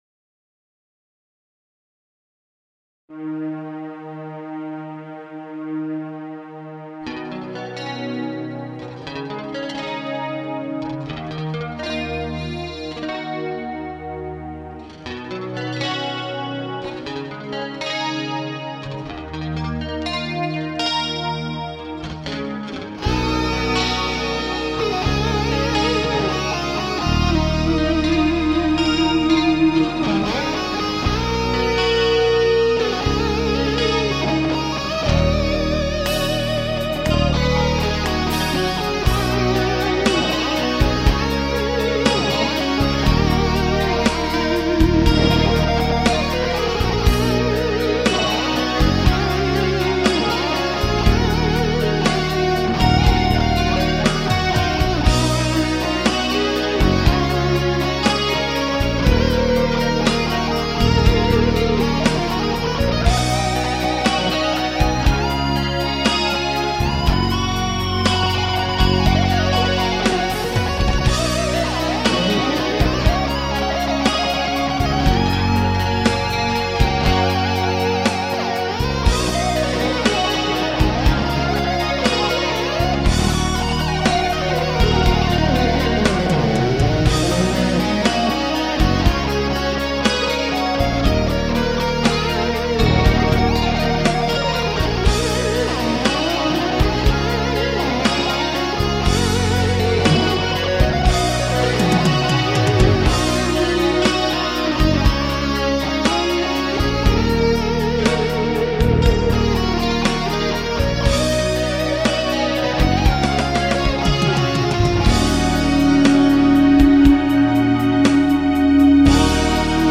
2000年　7月・・・ギター・インストロメンタル・バラード。フフフ〜哀愁ただよってます(笑)。
Cakewalkというソフトで自宅でなんとなく作ってみました。